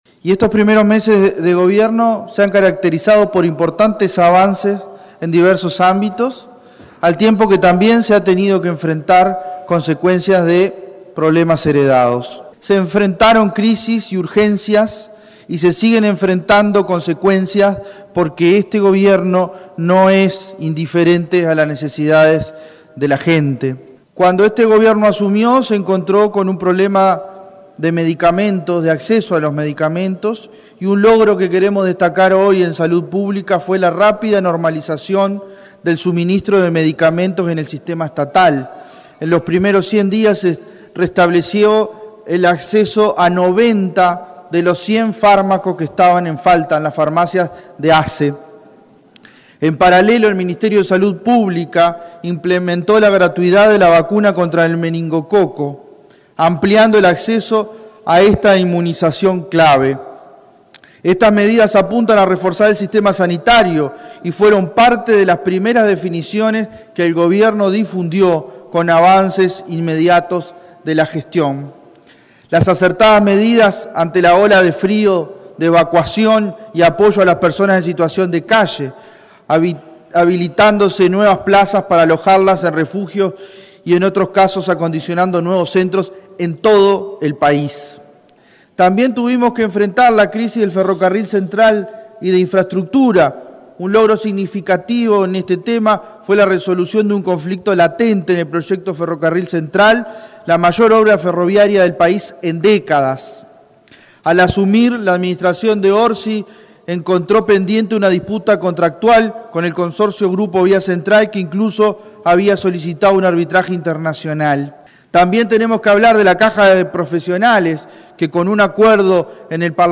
El senador Nicolás Viera habló en el parlamento sobre el restablecimiento del acceso a medicamentos en ASSE, a la implementación de la gratuidad a la vacuna contra el meningococo, las medidas tomadas ante la ola de frío y la importancia de resolver el conflicto en el ferrocarril central heredada del gobierno anterior, entre otros puntos.
NICOLAS-VIERA-SENADO.mp3